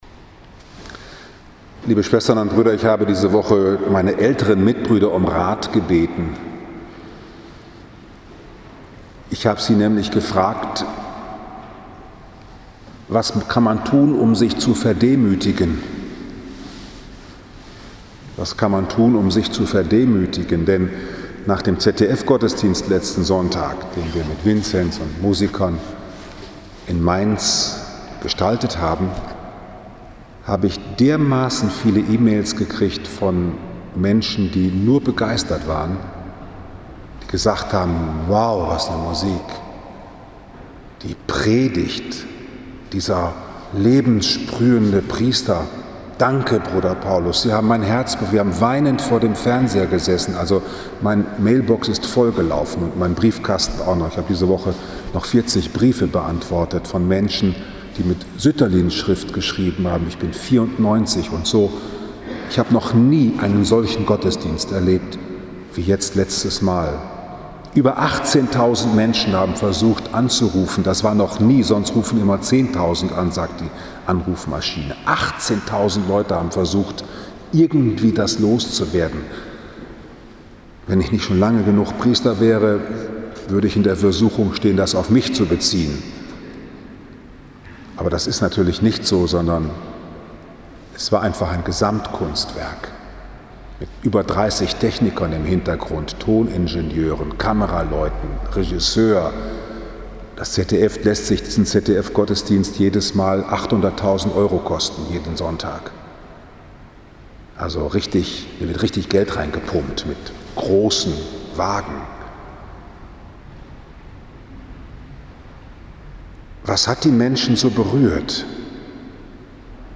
Von der Stärke des Christsein für Schöpfung und Mitmenschen 21. November 2020, 17 Uhr Liebfrauenkirche Frankfurt am Main, Christkönigssonntag A